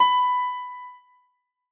Harpsicord
b5.mp3